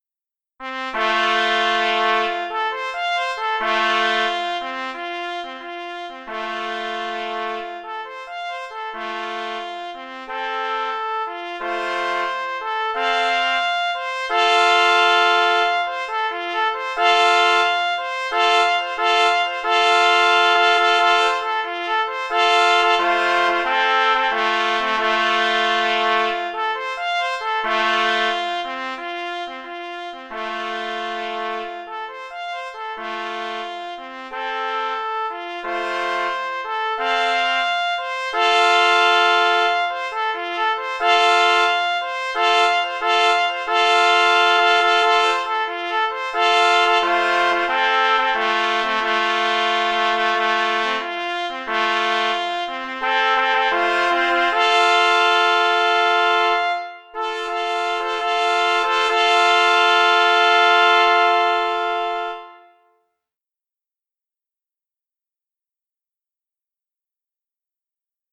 Opis zasobu: zapis w wersji oryginalnej na 3 trąbki in B Tytuł utworu: Hejnał Koła Łowieckiego „Hubert” w Płocku Kompozytor: Marcin […]
M. Kamiński – Hejnał KŁ – Hubert – w Płocku – na 3 trąbki in B – wersja oryginalna | MP3